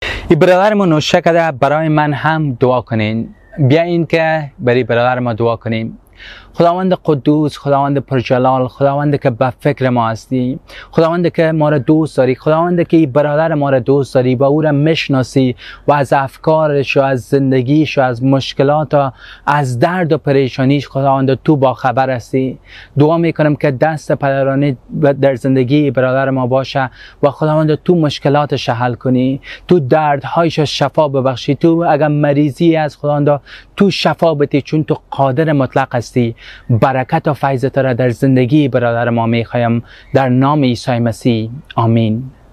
ٔدعا برای یک برادر که در مشکلات است